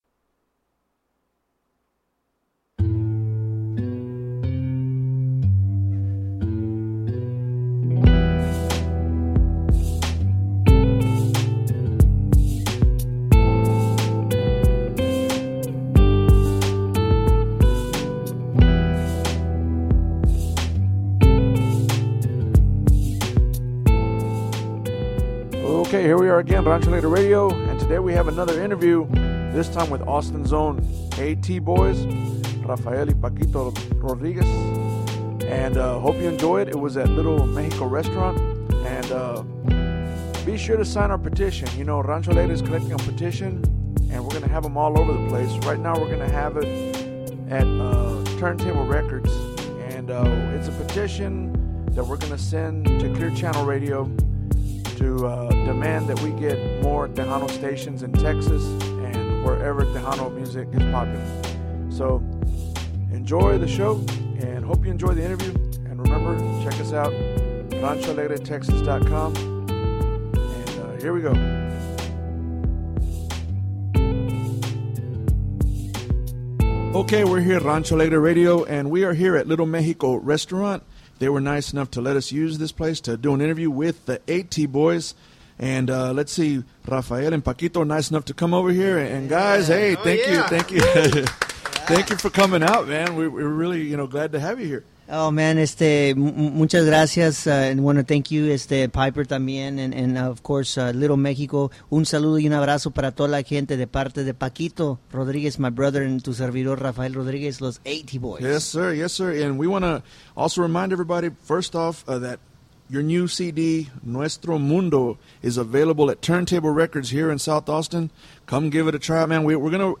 Rancho Alegre Interview - Los A-T Boyz